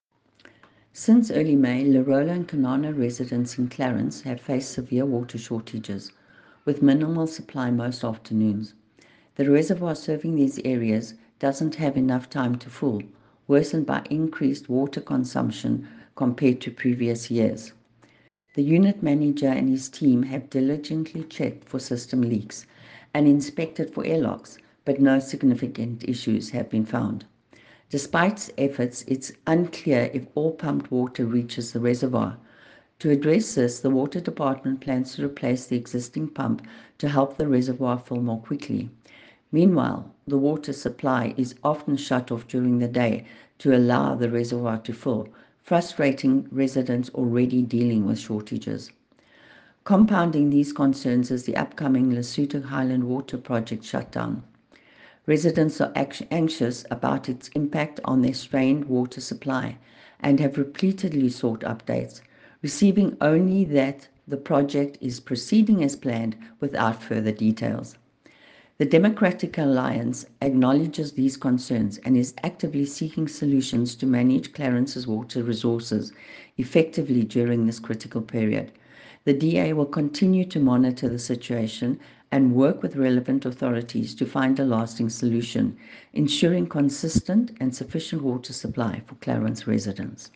English soundbite by Cllr Irene Rugheimer and